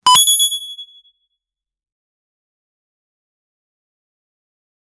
cash register clean sound effect for the game, no reverb.
cash-register-clean-sound-rmigb3zn.wav